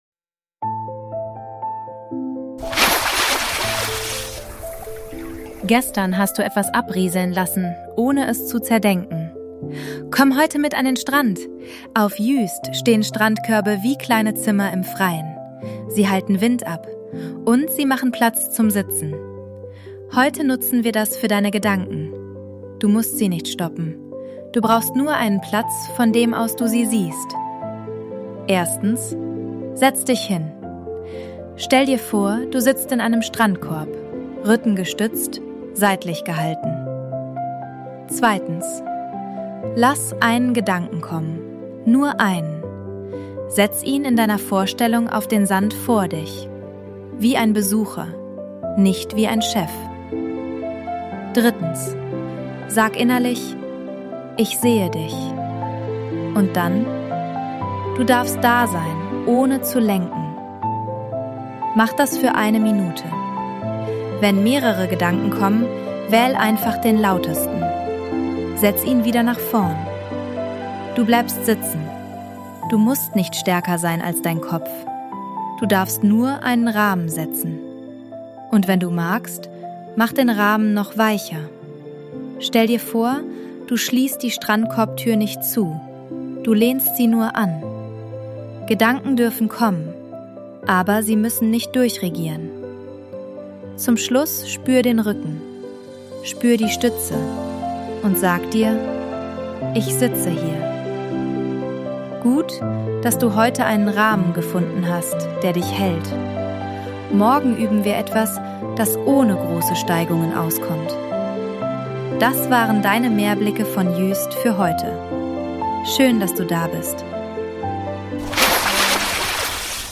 Sounds & Mix: ElevenLabs und eigene Atmos